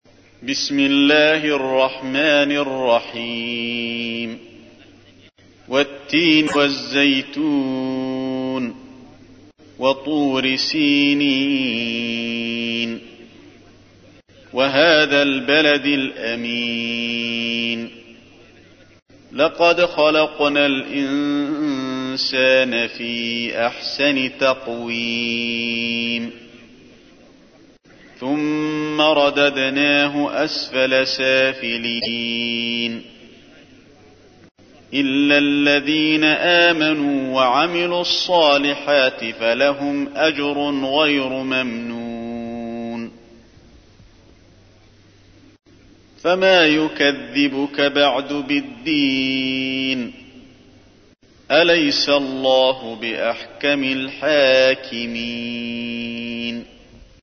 تحميل : 95. سورة التين / القارئ علي الحذيفي / القرآن الكريم / موقع يا حسين